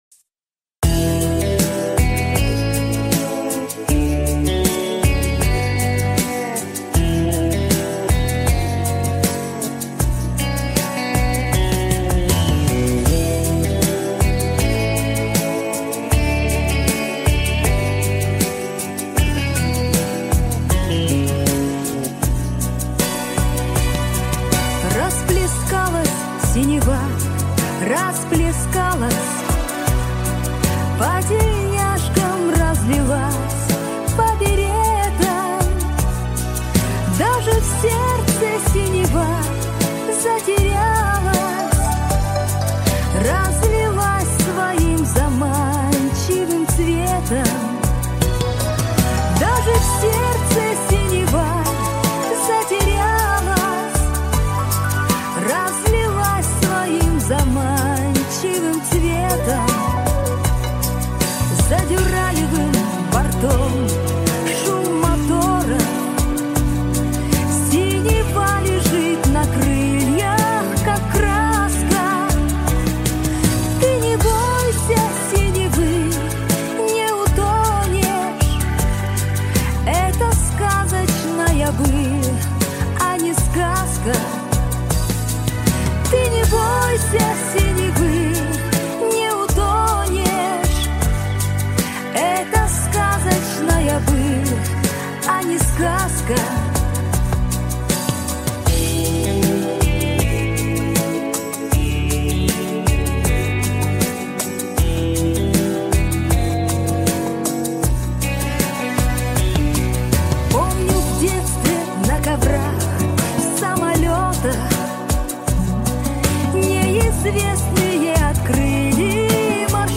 кавер
женский вокал